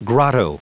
Prononciation du mot grotto en anglais (fichier audio)
Prononciation du mot : grotto
grotto.wav